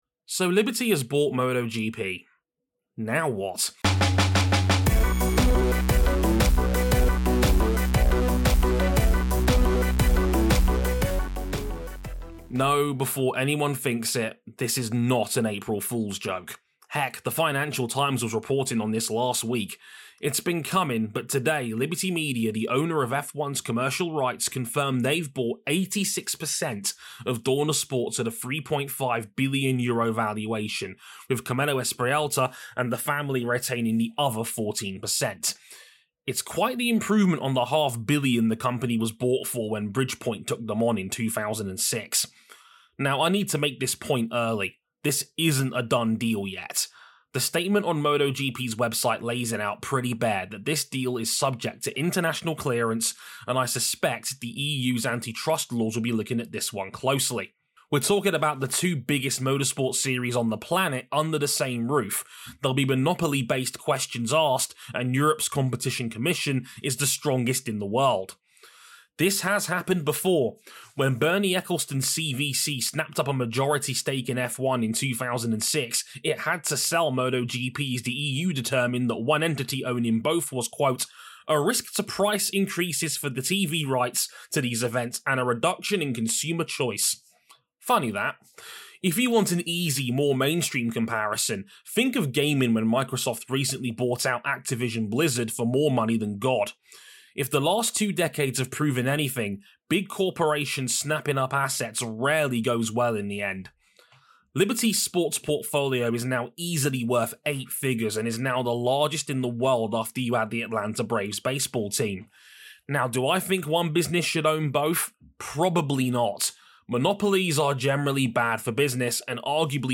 This is an audio track from a written article originally on Motorsport101